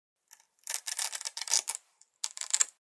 На этой странице собраны разнообразные звуки застежек и фермуаров от женских кошельков, сумок и других аксессуаров.
Медленно и неспешно